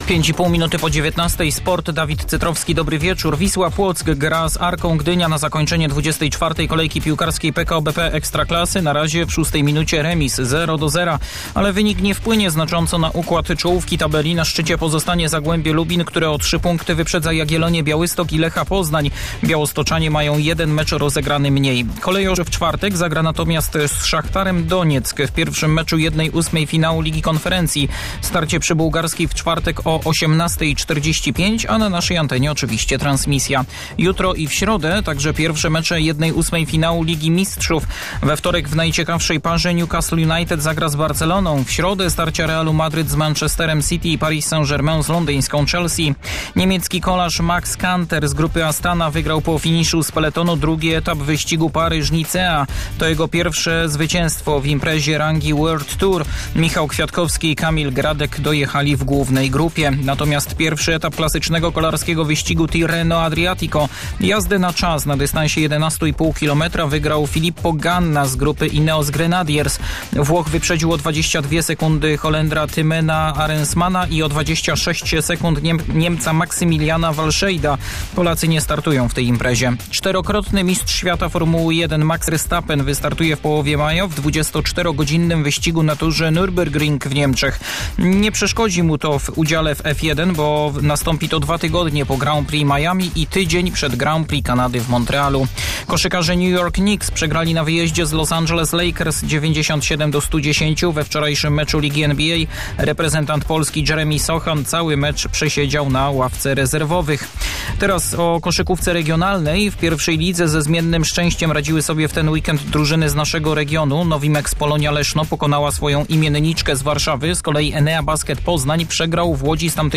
09.03.2026 SERWIS SPPORTOWY GODZ. 19:05